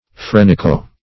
(fr[e^]n- or fr[=e]n-)